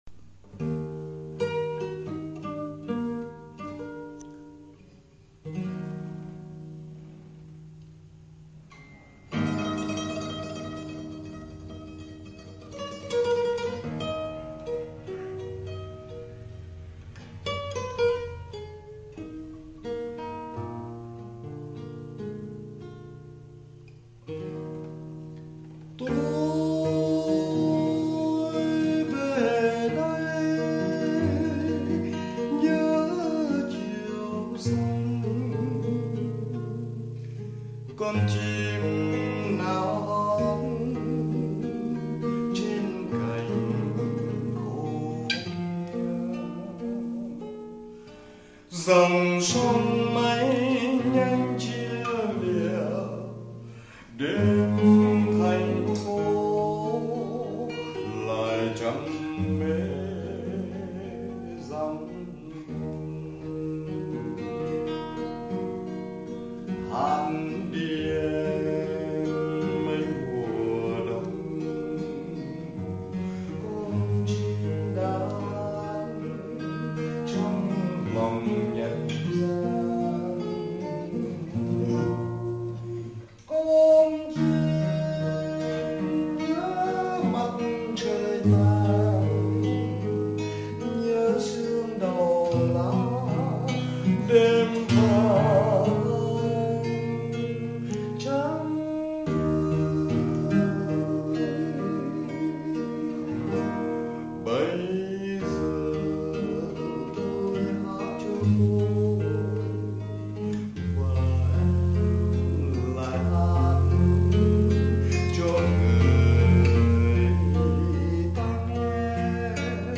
đàn và hát